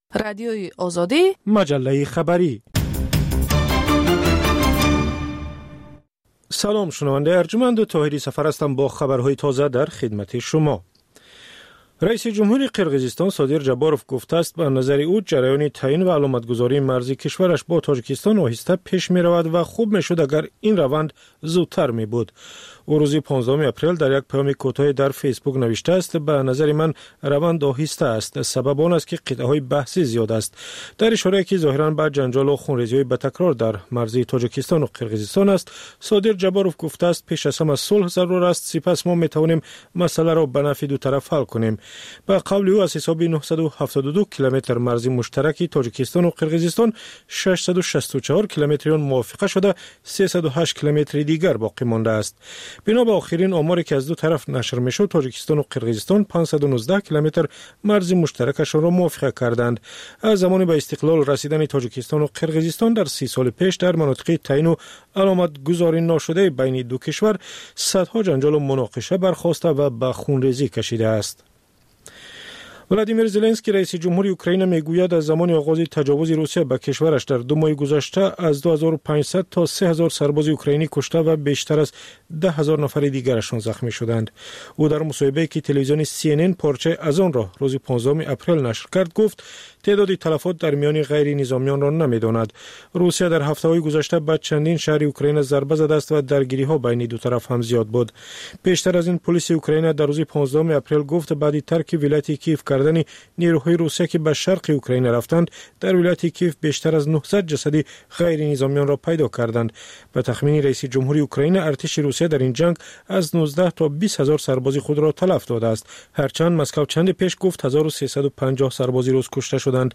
Тозатарин ахбор ва гузоришҳои марбут ба Тоҷикистон, минтақа ва ҷаҳон дар маҷаллаи бомдодии Радиои Озодӣ